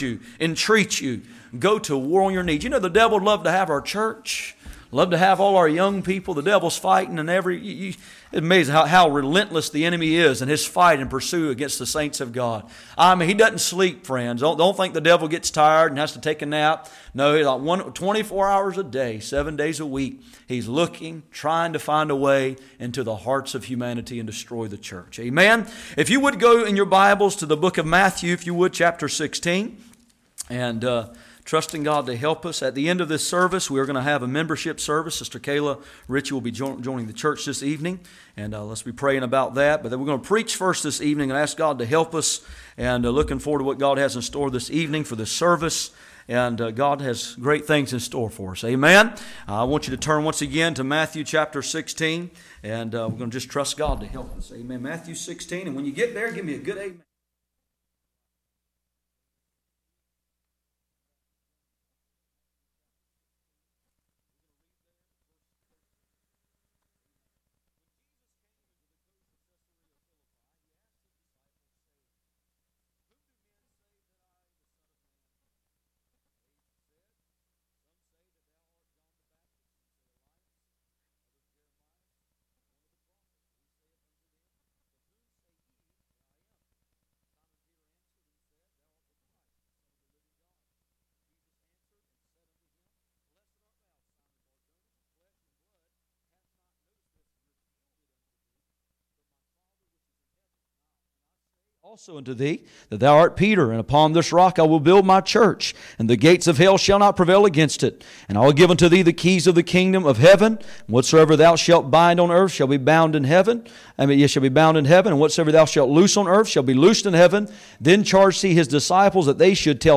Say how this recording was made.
Matthew 16:13-20 Service Type: Sunday Evening %todo_render% « The Sower